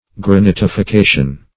Search Result for " granitification" : The Collaborative International Dictionary of English v.0.48: Granitification \Gra*nit`i*fi*ca"tion\, n. [Granite + L. -ficare (in comp.) to make.
granitification.mp3